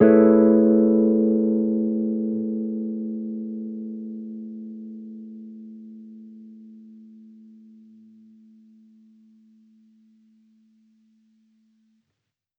Index of /musicradar/jazz-keys-samples/Chord Hits/Electric Piano 1
JK_ElPiano1_Chord-Amaj9.wav